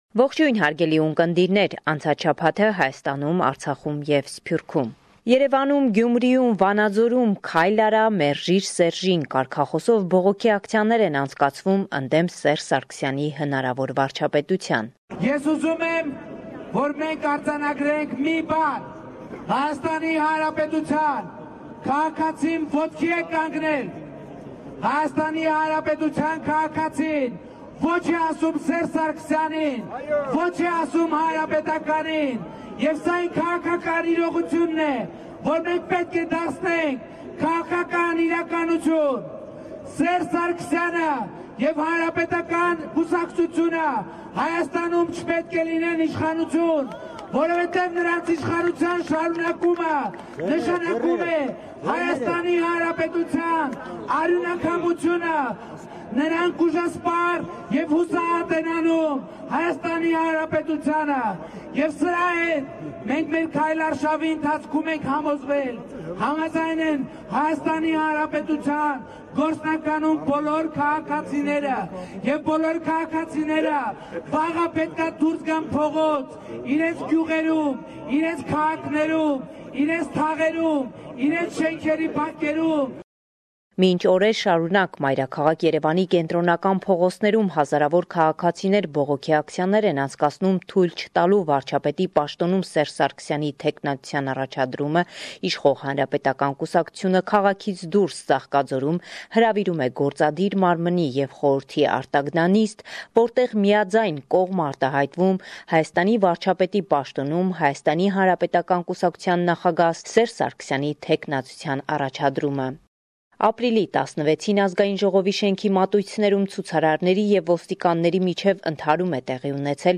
Վերջին Լուրերը – 17 Ապրիլ 2018